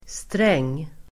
Uttal: [streng:]